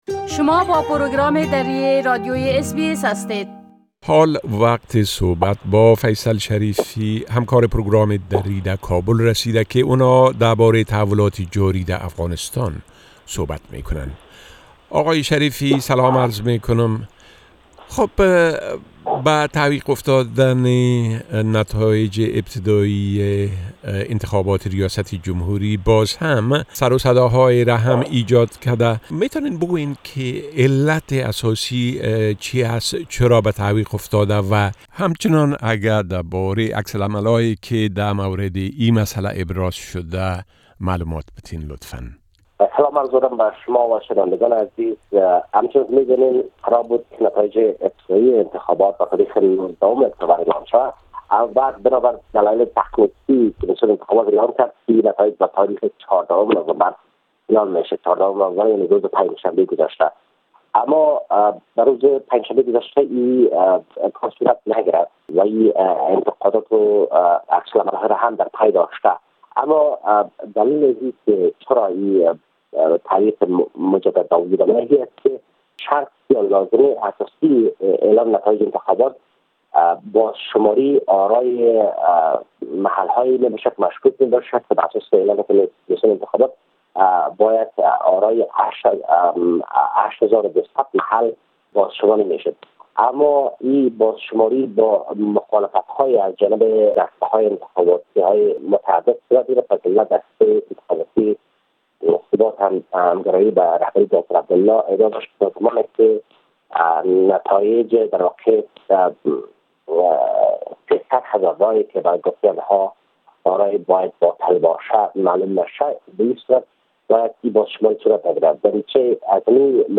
گرازش كامل خبرنگار ما در كابل به شمول وضعيت امنيتى و رويداد هاى مهم ديگر در افغانستان را در اينجا شنيده ميتوانيد.